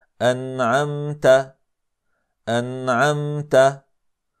Det ska uttalas på följande sätt: